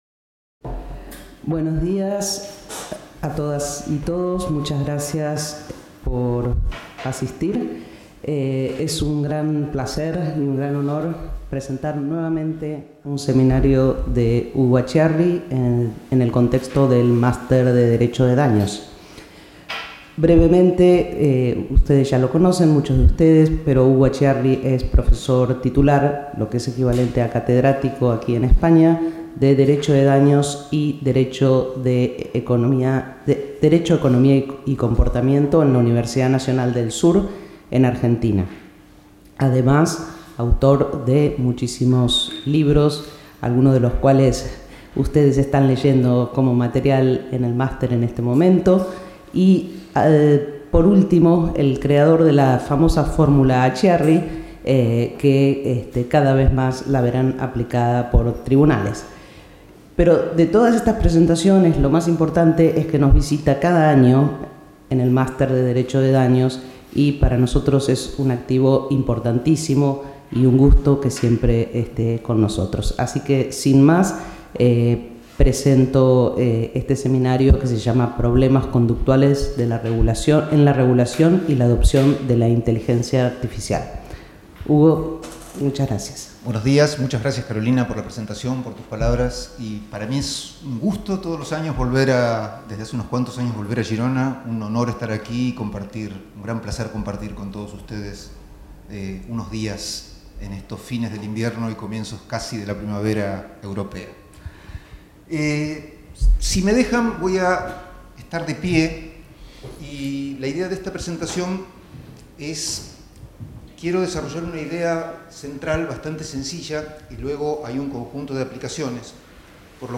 Conference